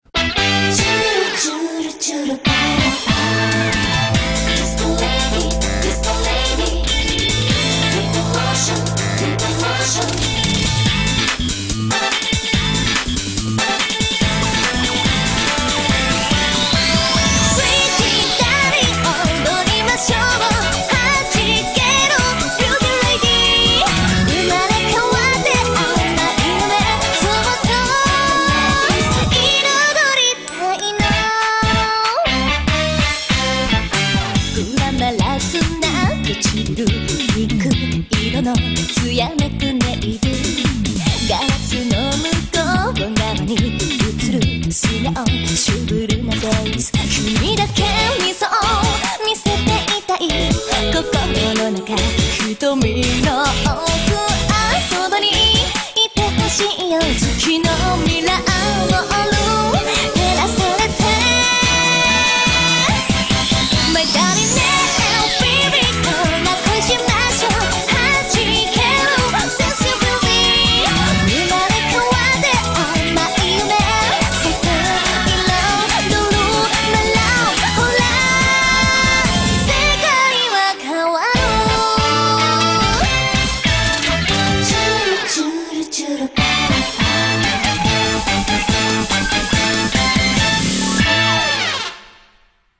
BPM143
Audio QualityCut From Video